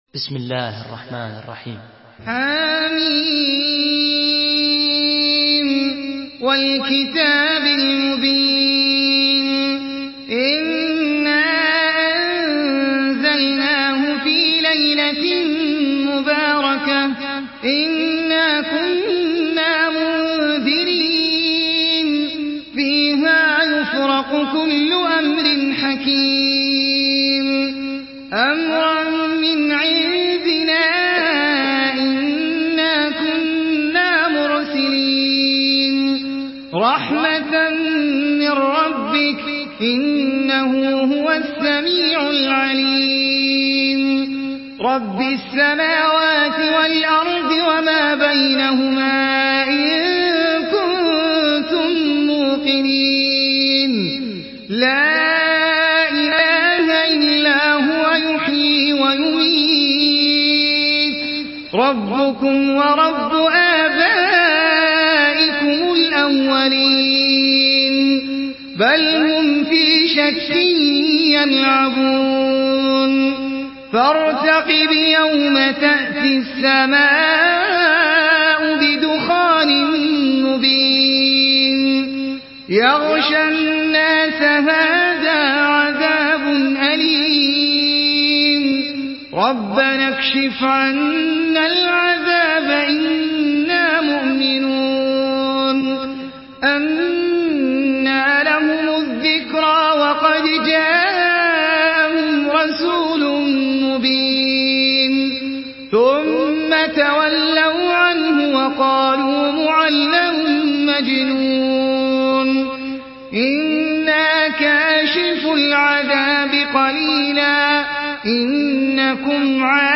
Surah Duhan MP3 by Ahmed Al Ajmi in Hafs An Asim narration.
Murattal Hafs An Asim